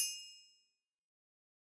SouthSide Trap Bell (2).wav